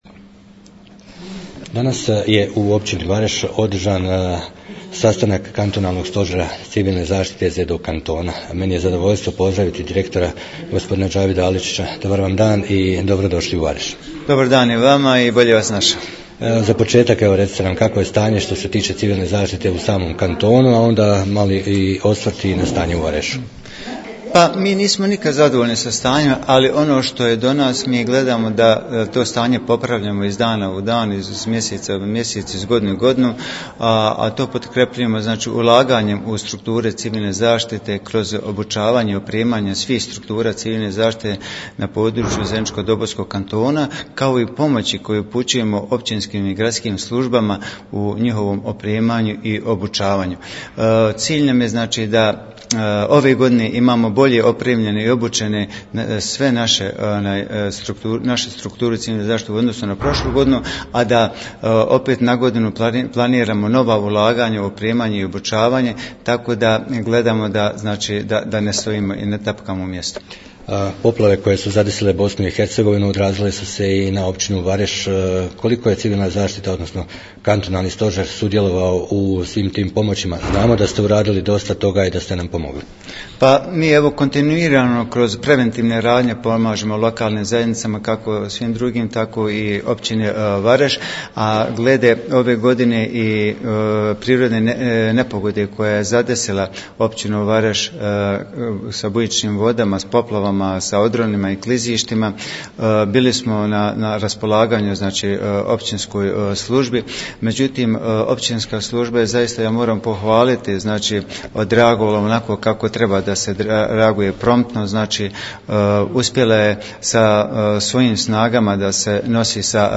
Poslušajte izjavu direktora kantonalne uprave Civilne zaštite Džavida Aličića nakon završene sjednice stožera .........